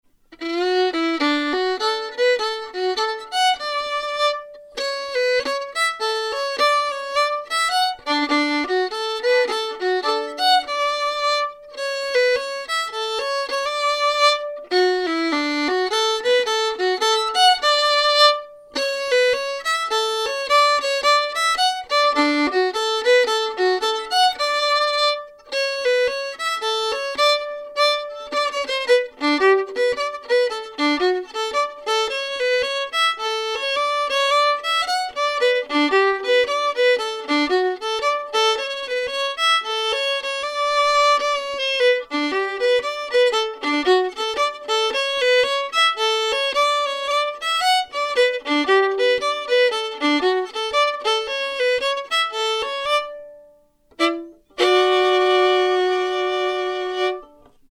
The tune is in the key of D and is in 3/4 time.
It’s got a lightly dotted feel and many of the “holds” are schottische-like.